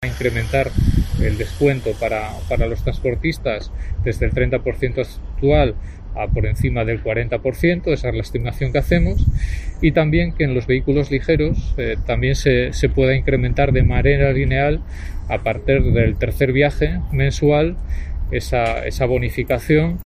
Alejandro Calvo explica la propuesta de bonificaciones en el Huerna